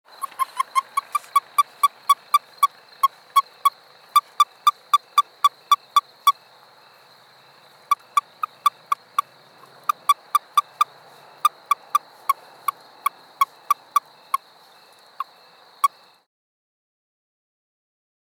Release Calls
A release call is produced by a male toad or an unreceptive female toad when a frog or other animal (including a human hand) grabs it across the back in the position used for mating or amplexus.
Sound  This is a 14 second recording of the release calls of an Amargosa Toad (sex not determined) made at night in Nye County, Nevada. Insects are heard in the background.